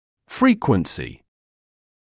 [‘friːkwənsɪ][‘фриːкуэнси]частота